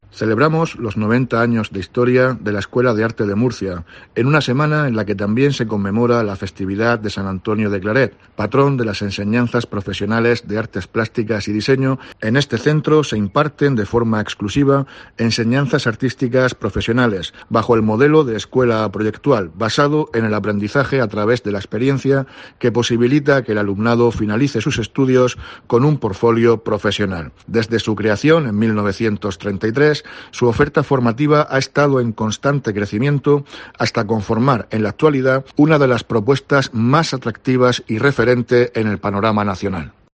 Víctor Marín, consejero Educación, Formación Profesional y Empleo